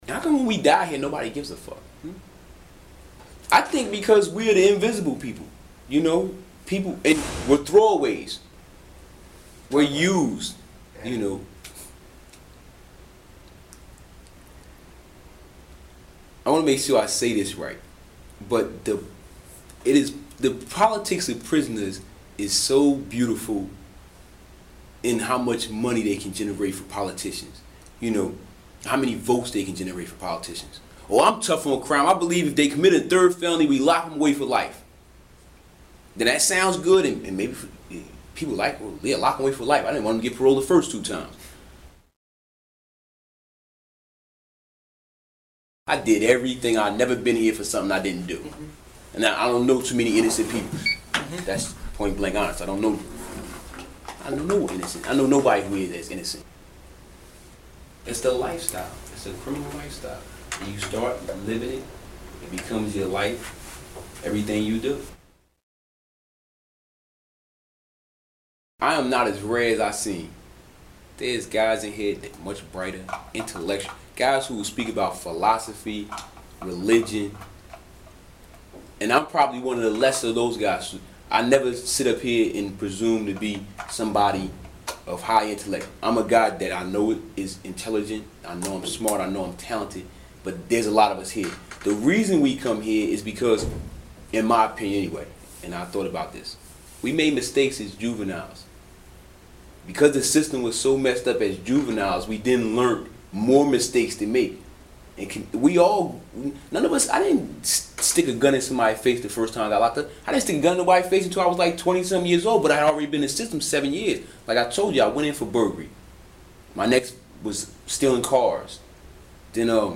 Lockdown is a photography and interview project that tells the story of a society that imprisons over 2 million people from the viewpoint of those locked down. I work with inmates — photographing them and hearing their stories. The project is presented as a series of 20″ x 24″ black and white photographs accompanied by carefully edited audio from the interviews.
Lockdown 2000-2004, Gelatin Silver Prints, spoken word audio, all prints 24 x 20 inches.